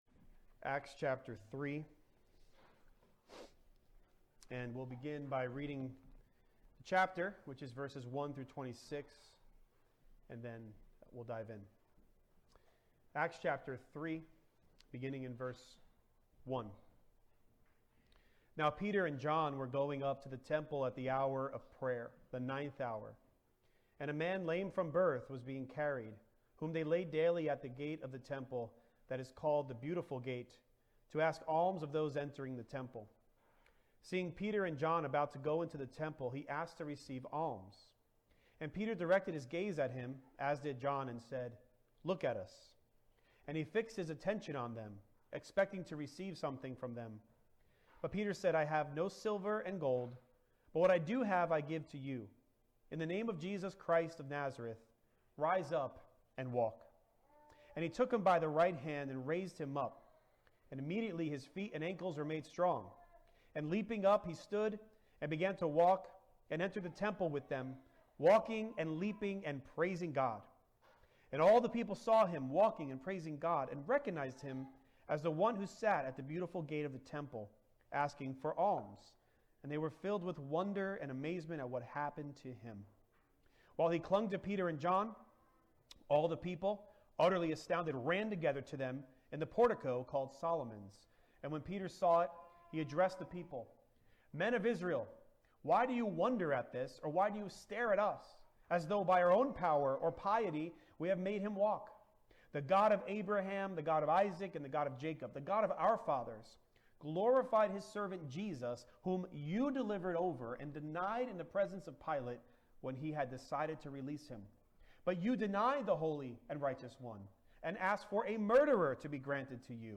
The Blessing of Turning | SermonAudio Broadcaster is Live View the Live Stream Share this sermon Disabled by adblocker Copy URL Copied!